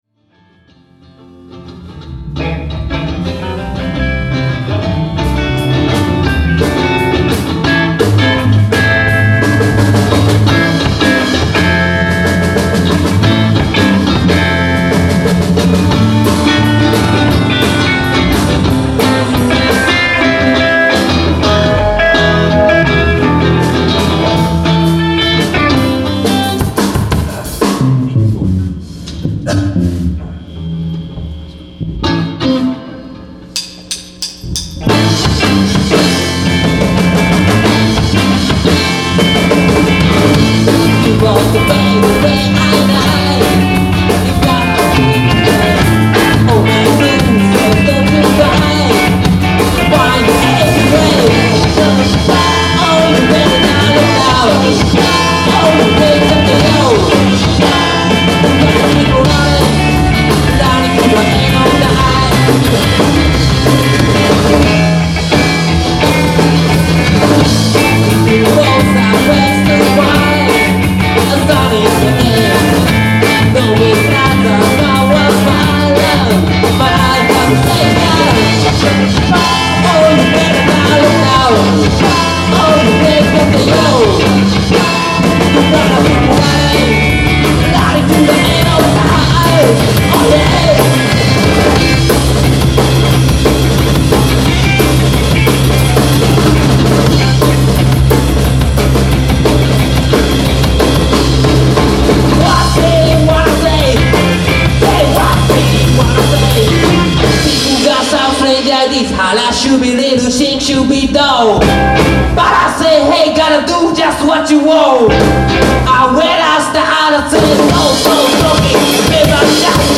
スタジオで練習でした。
いきなり出だしで、声が裏返ってます。
こっちも初っぱな歌詞間違えました。